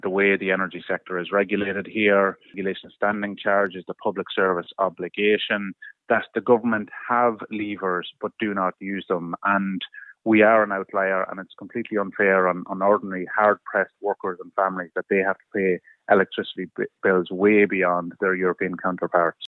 Sinn Fein’s Darren O’Rourke says the government should use the tools at it’s disposal to bring down costs: